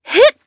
Shamilla-Vox_Jump_jp.wav